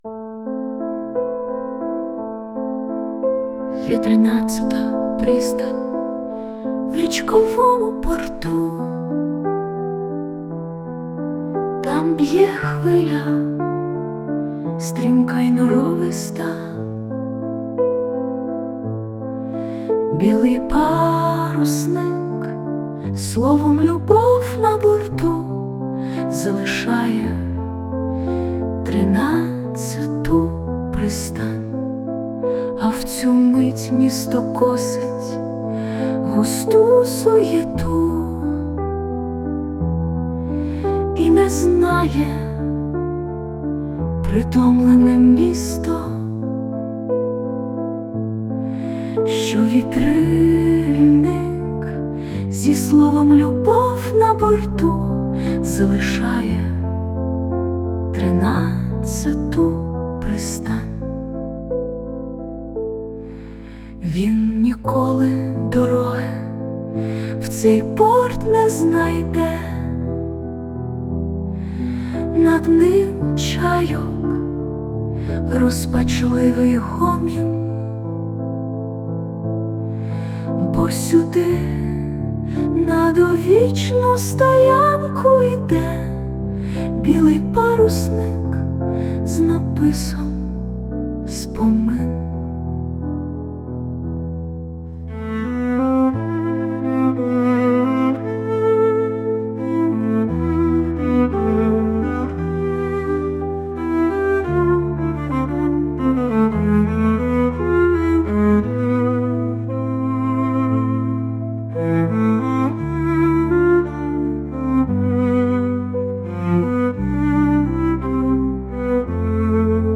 музику і виконання згенеровано ШІ)
Чудова пісня!